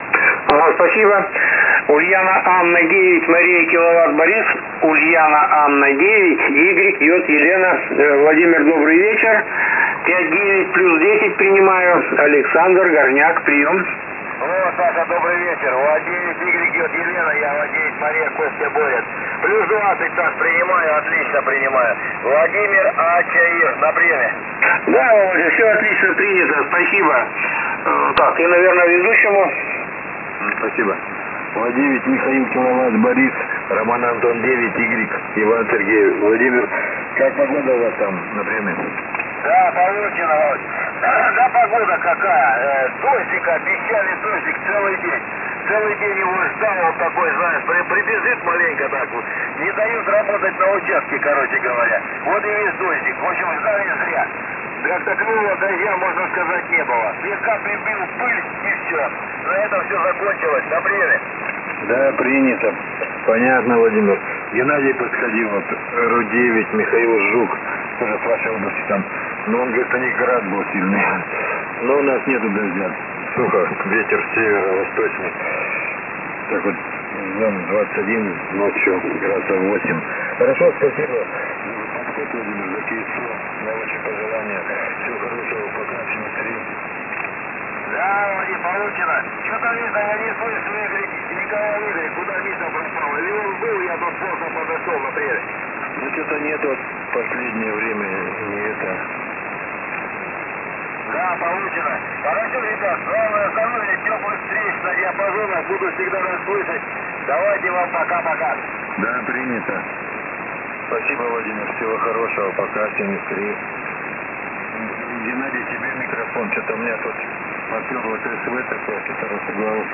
§4.5.4 Проверка LSB на слух.
С целью оценки правильности подбора времени срабатывания АРУ стоит настроиться на что-то местное, стесняющееся звучать без «плюсов», и послушать, как ведёт себя тракт в паузах между словами, особенно в тех самых «плюсах». К примеру, вот запись вечернего ритуала прощания:
Динамический диапазон по выходному сигналу примерно 15 dB:
Тут полоса приёма по низкой частоте выставлена от 200 до 2700 Hz, поэтому «дыру Уивера» надо визуально искать в районе 1450 Hz - SSB телефон на представленной выше записи спектрально выглядит примерно так:
Несмотря на довольно мощные эфирные сигналы, инверсная составляющая метода Уивера себя не проявляет.